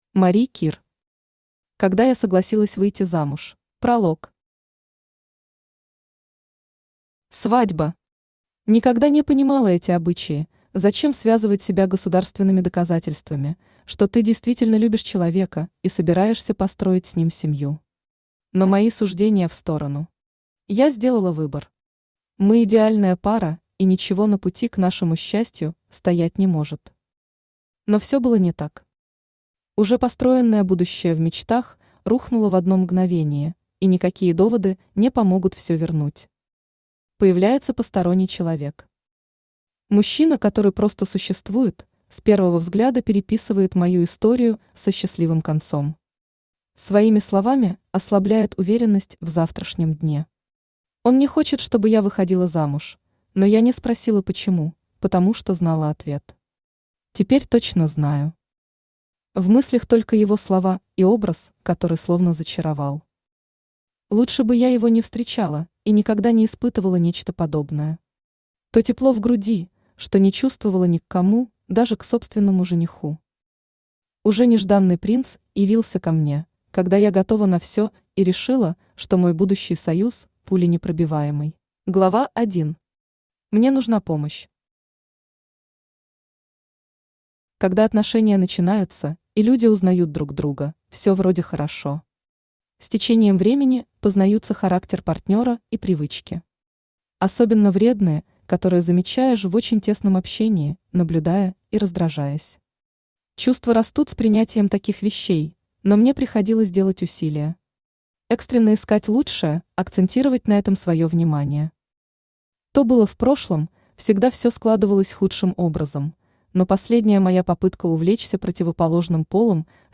Аудиокнига Когда я согласилась выйти замуж | Библиотека аудиокниг
Aудиокнига Когда я согласилась выйти замуж Автор Мари Кир Читает аудиокнигу Авточтец ЛитРес.